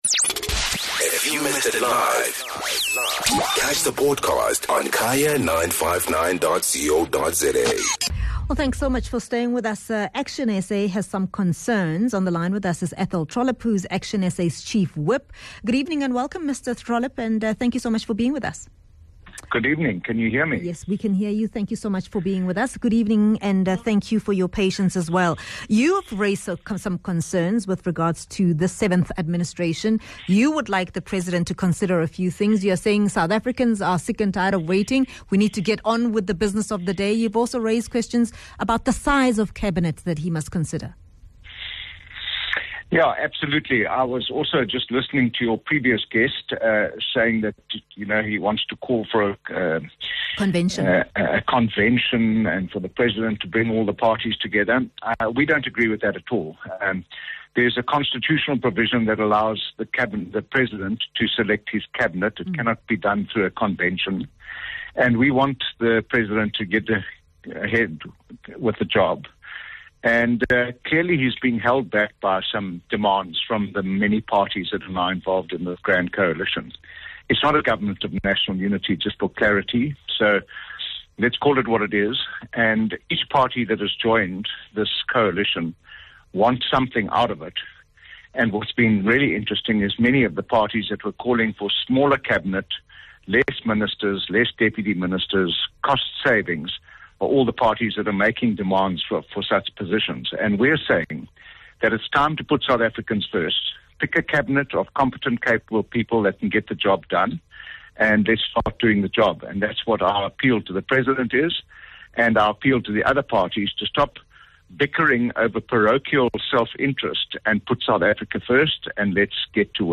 Guest: Athol Trollip - ACTIONSA Chief Whip